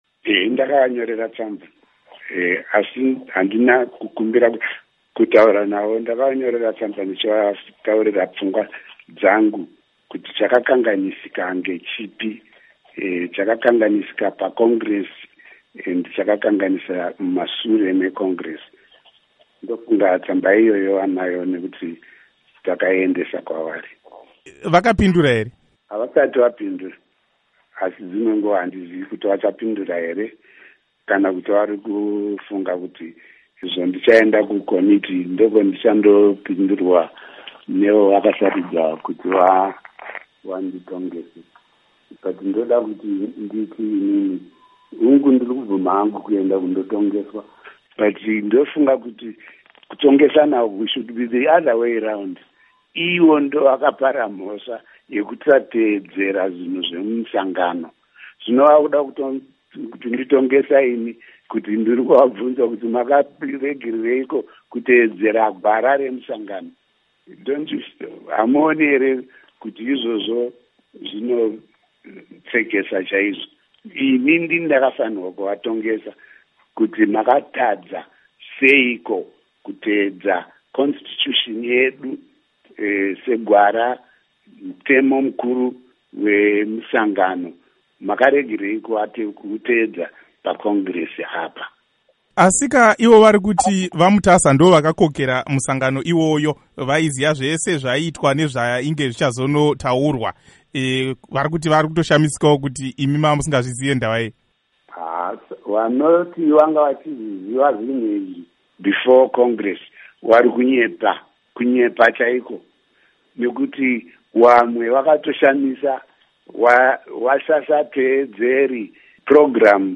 Hurukuro naVaDidymus Mutasa